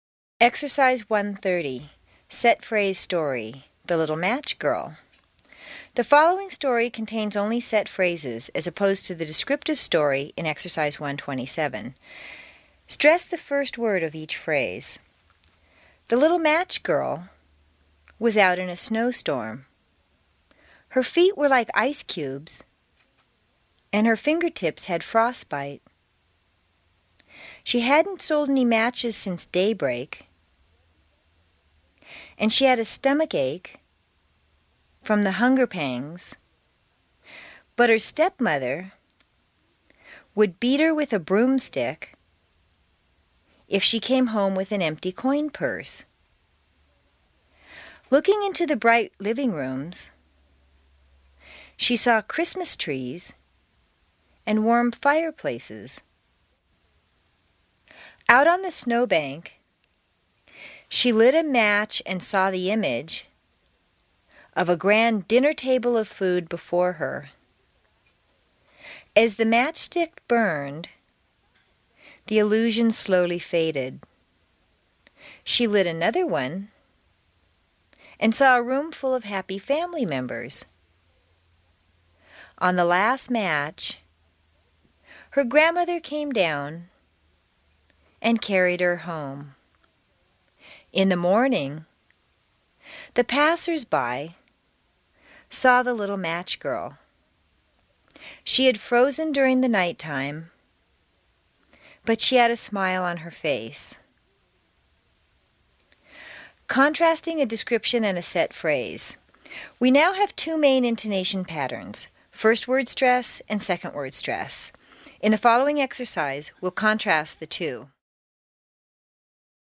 Stress the first word of each phrase.